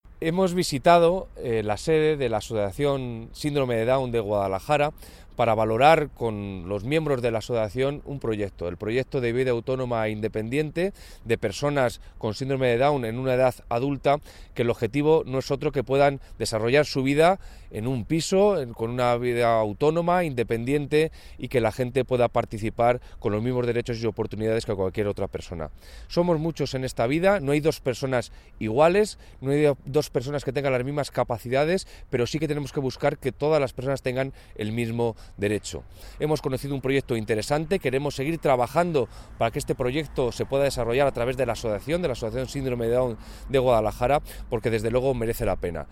El delegado de la Junta en Guadalajara, Alberto Rojo, habla de la colaboración del Gobierno regional con la Asociación Síndrome de Down de Guadalajara.